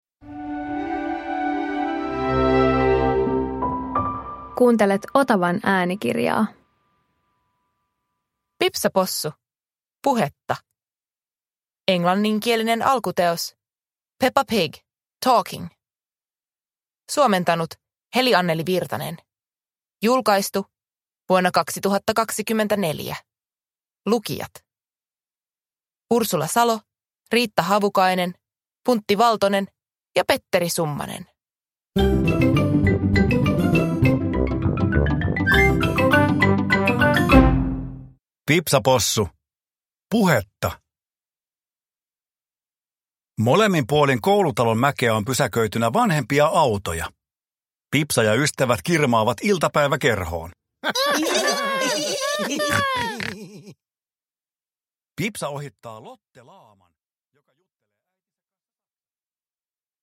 Pipsa Possu - Puhetta – Ljudbok